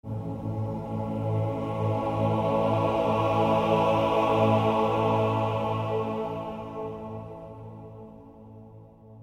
سمپل گروه کُر برای فضاسازی | دانلود آواهای دسته جمعی
demo-choir.mp3